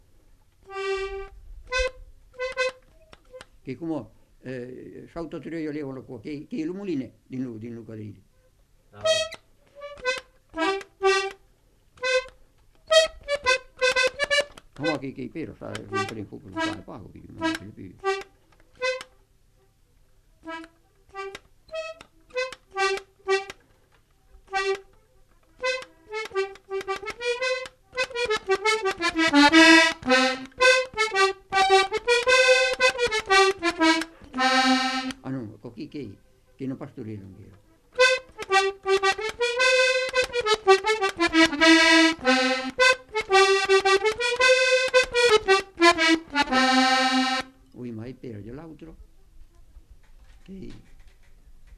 Genre : morceau instrumental
Instrument de musique : accordéon diatonique
Danse : quadrille